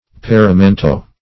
paramento - definition of paramento - synonyms, pronunciation, spelling from Free Dictionary Search Result for " paramento" : The Collaborative International Dictionary of English v.0.48: Paramento \Pa`ra*men"to\, n. [Sp.] Ornament; decoration.